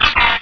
pokeemerald / sound / direct_sound_samples / cries / cacnea.aif
cacnea.aif